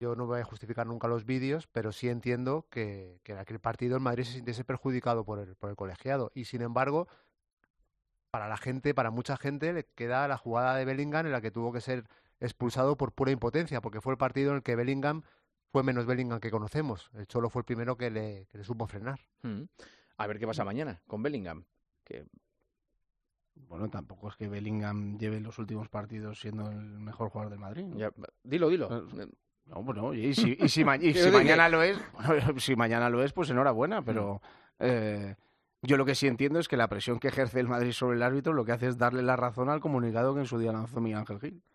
Los vídeos de la televisión blanca sobre los árbitros siguen dando que hablar y el tertuliano recordó a Juanma Castaño en El Partidazo de COPE esta circunstancia